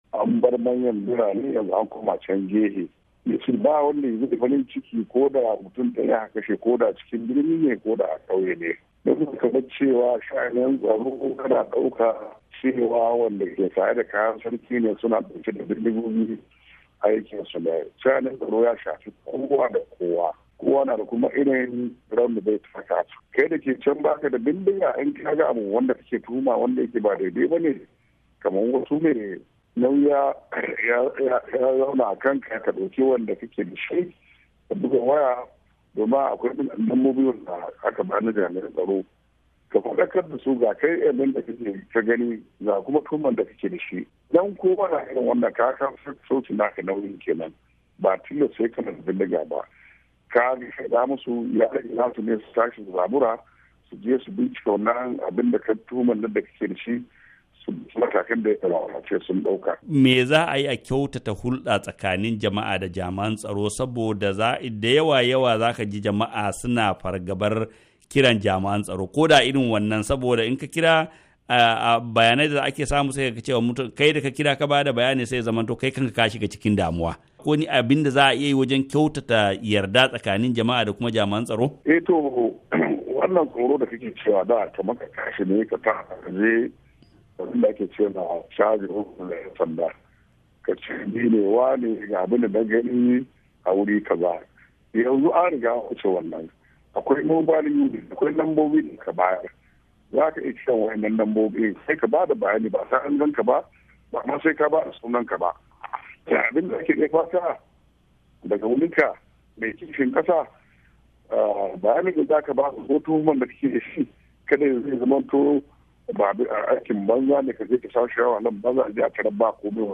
Hira da Senator Mamman Magoro - 3:06